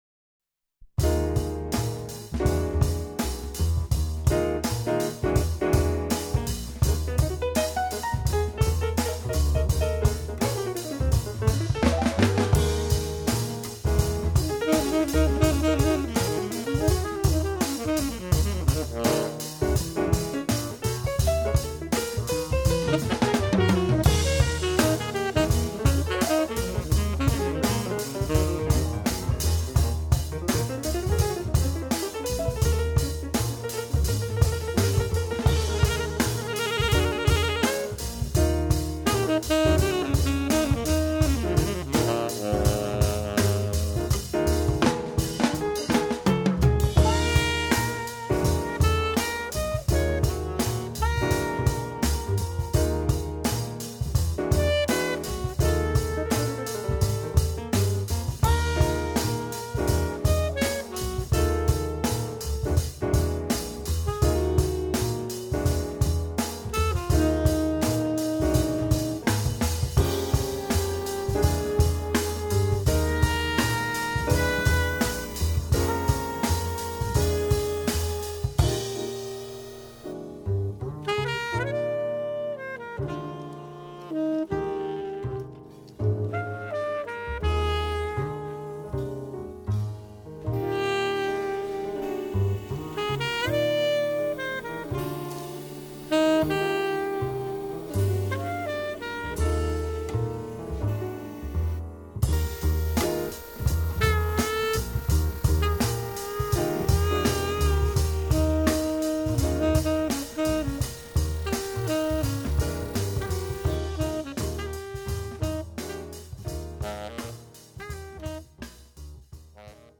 alto sax
pianoforte
batteria.